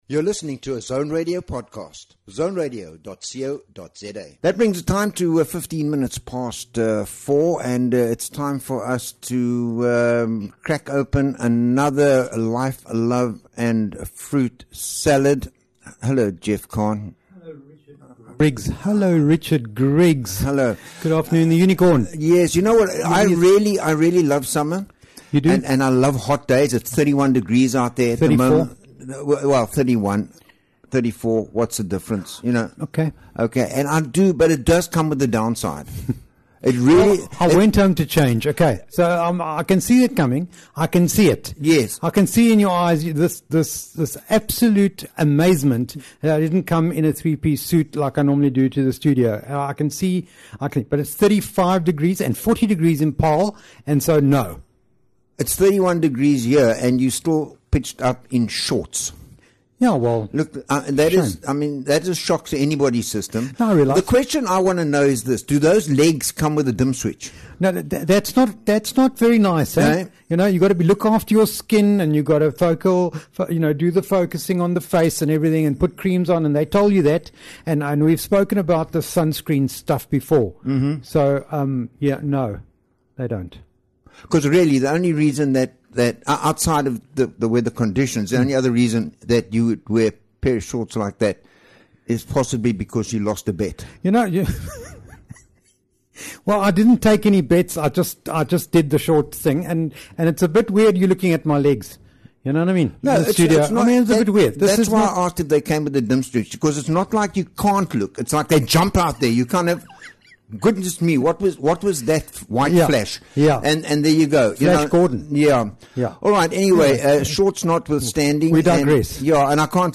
is a bi-weekly radio show exploring the rich tapestry of human experiences. Delve into the sweet and tangy moments of life, savoring conversations on relationships, wellbeing, and the flavors that make up our existence.